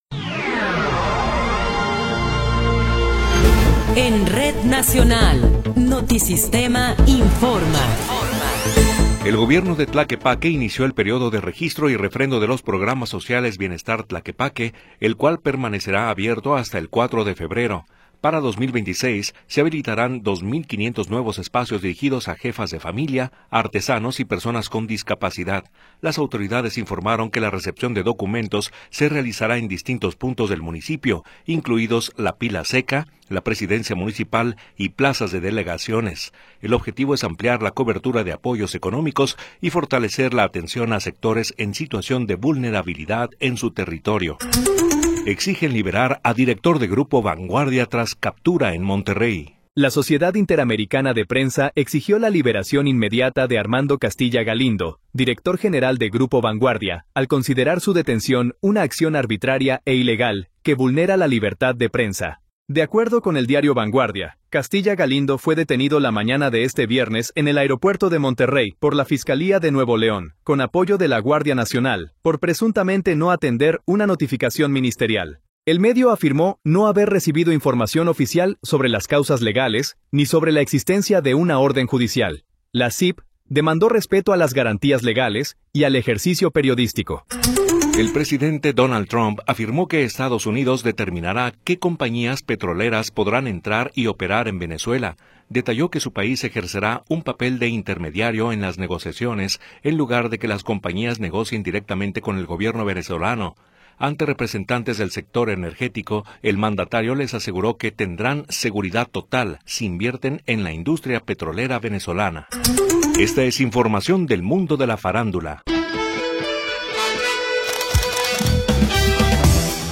Noticiero 19 hrs. – 9 de Enero de 2026